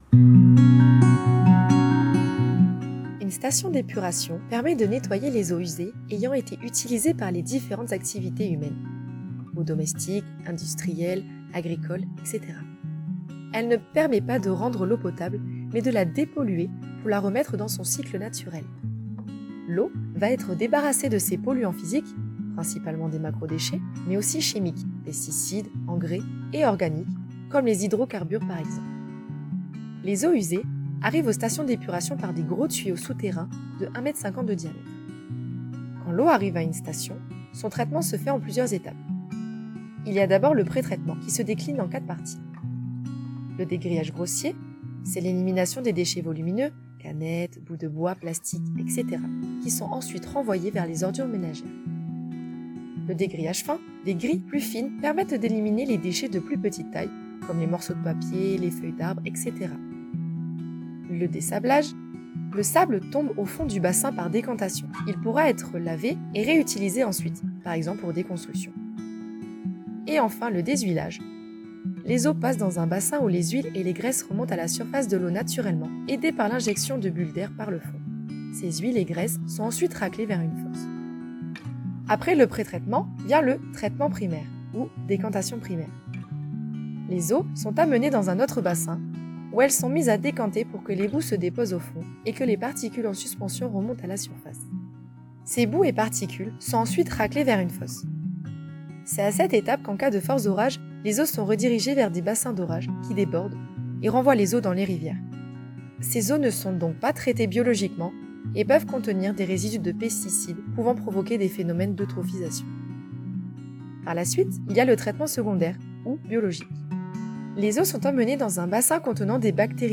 Intervenante